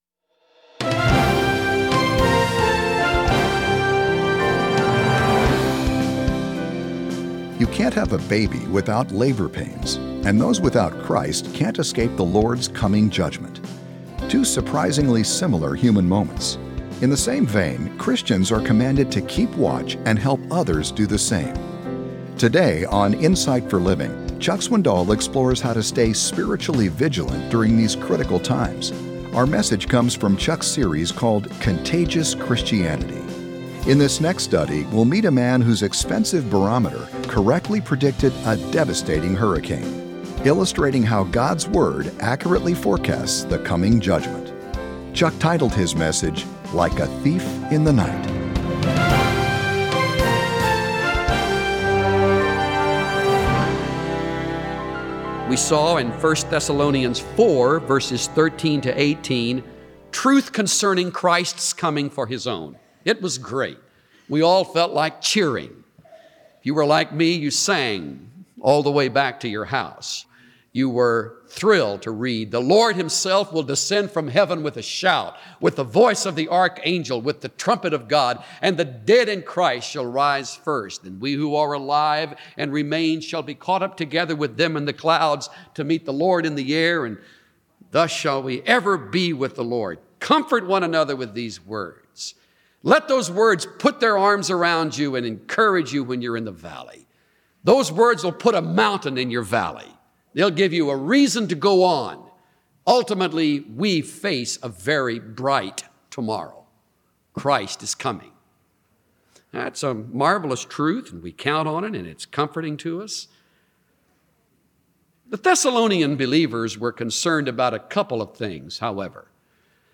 But Paul assures them of their eternal security in Christ. Join Pastor Chuck Swindoll as he explains “the day of the Lord” from 1 Thessalonians 5:1–11.